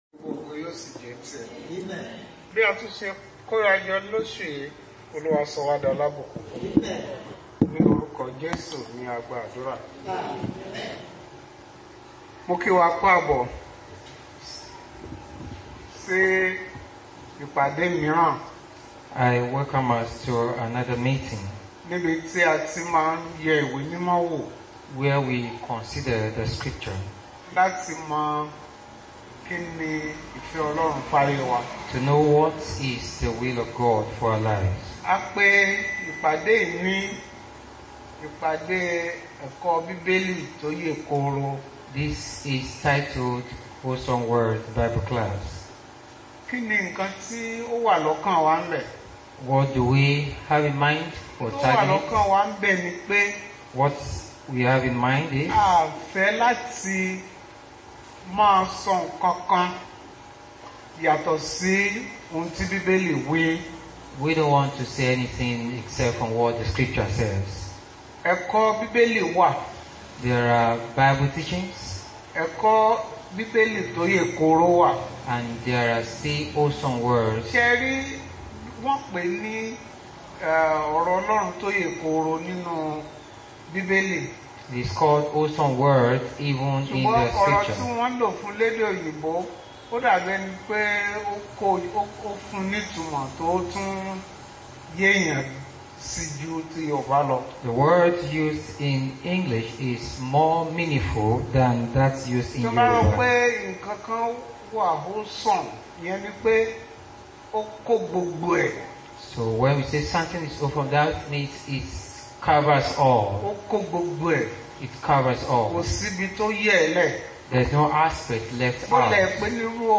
Bible Class Passage: Acts 9:15-16, Acts 20:17-38